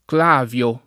vai all'elenco alfabetico delle voci ingrandisci il carattere 100% rimpicciolisci il carattere stampa invia tramite posta elettronica codividi su Facebook Clavio [ kl # v L o ] cogn. — nome umanistico del matematico Ch. Schlüsse (1537-1612)